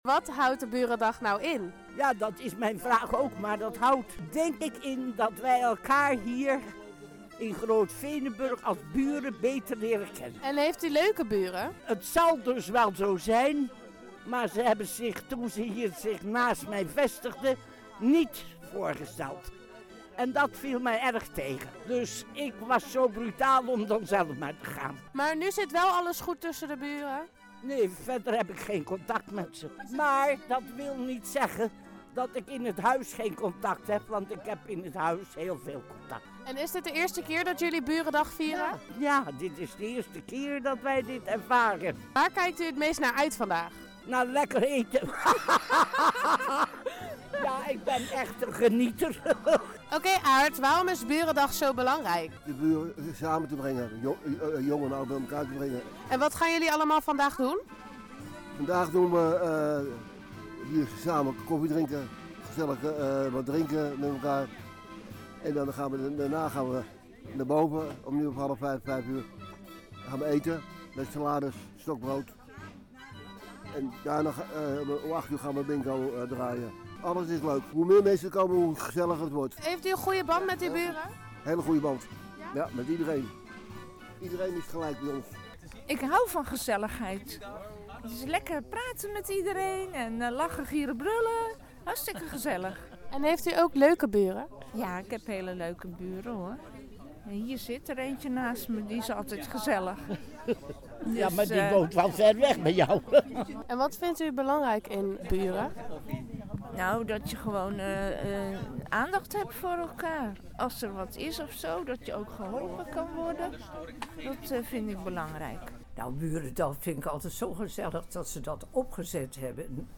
Burendag werd in Hillegom vrijdag al afgetrapt. Appartementencomplex Groot Veenenburg organiseerde een koffiemiddag, waar ook burgemeester Roberto ter Hark op afkwam.